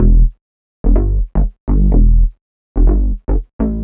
cch_bass_loop_room_125_Em.wav